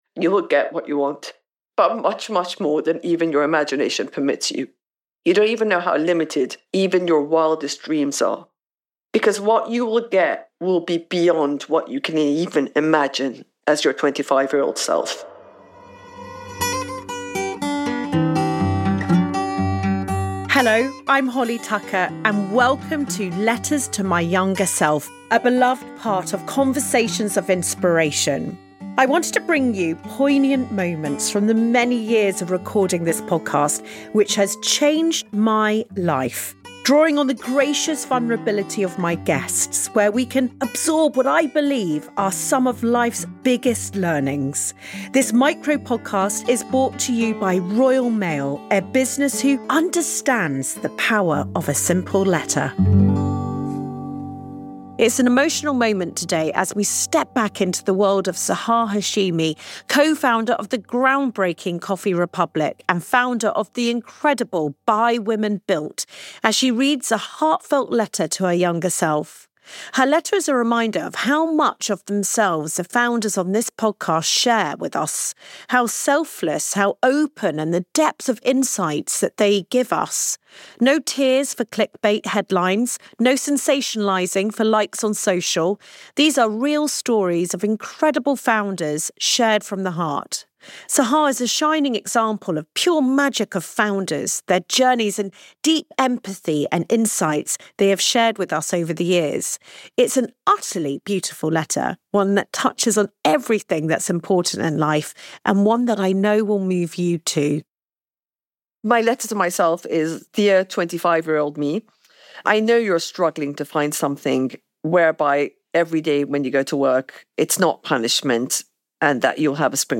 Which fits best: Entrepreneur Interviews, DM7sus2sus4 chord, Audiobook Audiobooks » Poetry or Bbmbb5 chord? Entrepreneur Interviews